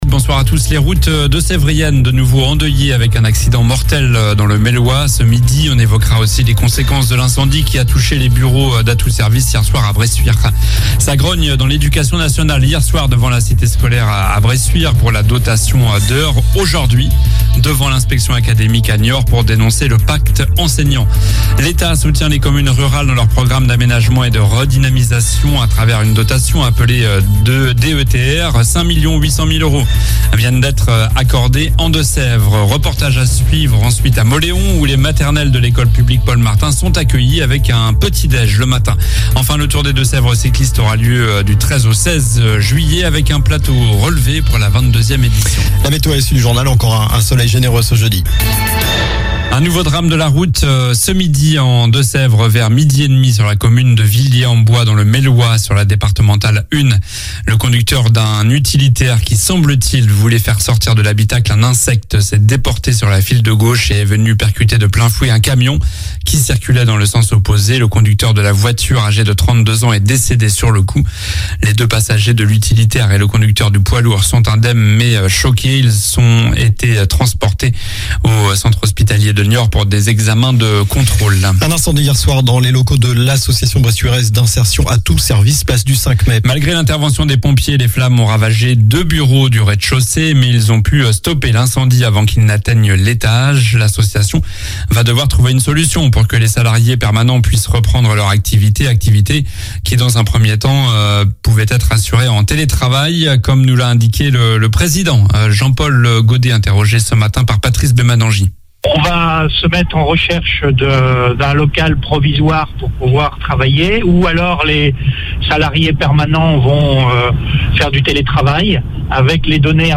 Journal du mercredi 31 mai (soir)